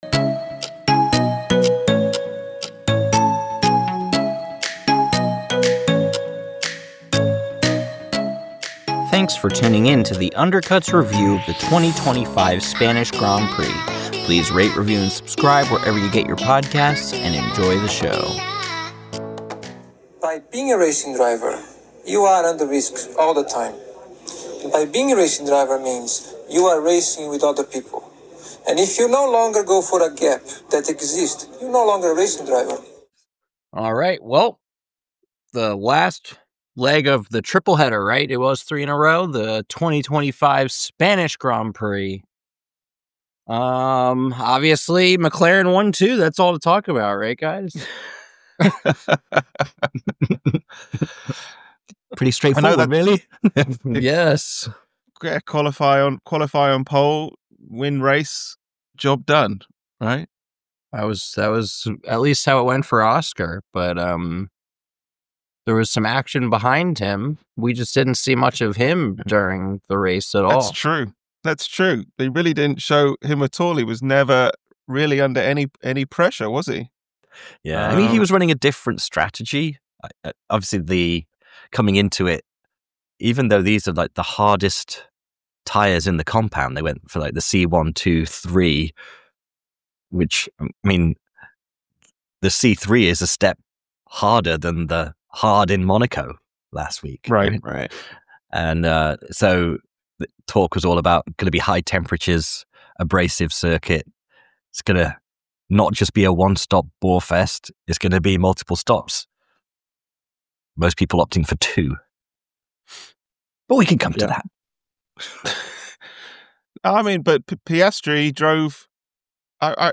The McLarens have another dominant win with Oscar widening his lead in the Drivers Championship, but do Max's actions take away from the racing? The Undercut Guys get into that and much more in their review of the 2025 Spanish Grand Prix.